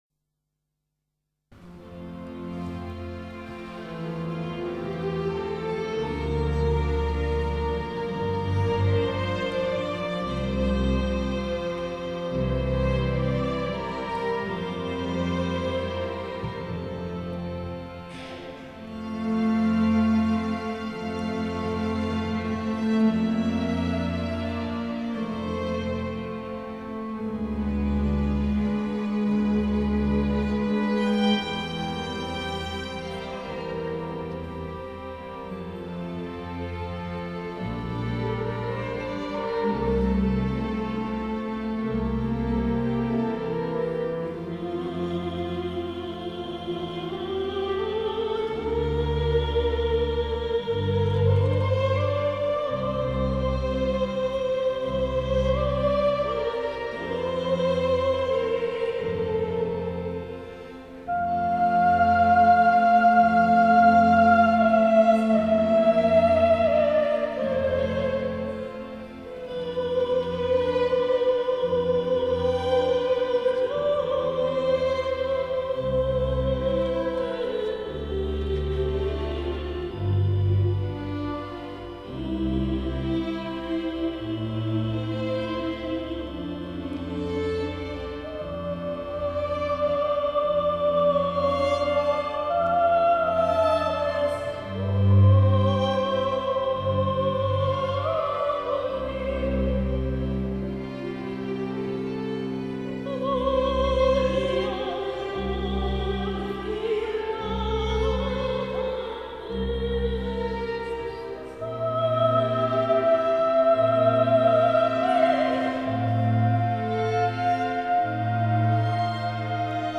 Nahrávky obsahují výběr z klasických i muzikálových děl – F. Loewe: My Fair Lady (Líza), F. Lehár: Veselá vdova (duet Hana Glawari a hrabě Danilo), J. Haydn: Stvoření (duet Adama a Evy ze třetí části) a W. A. Mozart: Laudate Dominum v podání sopránové árie.